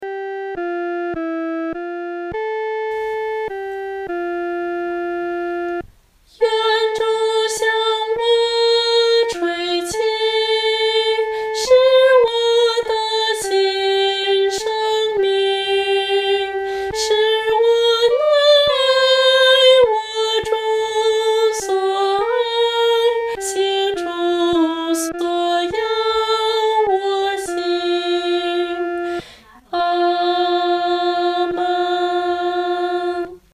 独唱
女高